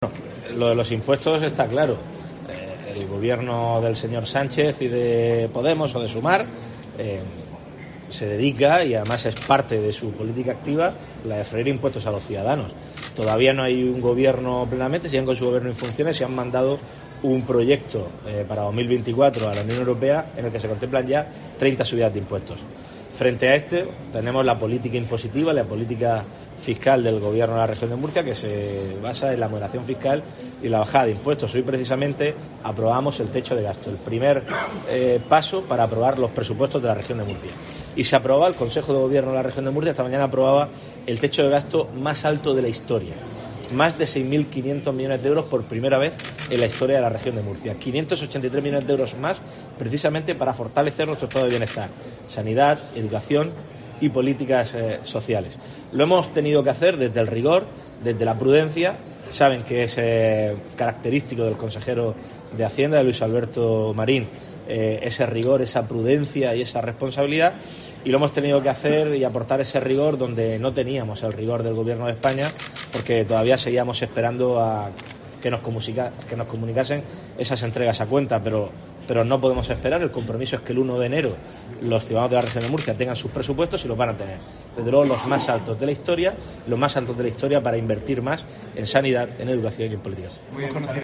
En un contacto con los medios de comunicación y al ser preguntado por el debate de investidura, López Miras ha criticado que se haya establecido la fecha "en 24 horas", cuando al presidente del PP, Alberto Núñez Feijóo, "se le fechó con más de 30 días de antelación".